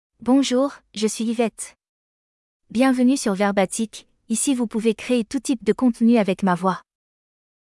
FemaleFrench (France)
YvetteFemale French AI voice
Yvette is a female AI voice for French (France).
Voice sample
Female
Yvette delivers clear pronunciation with authentic France French intonation, making your content sound professionally produced.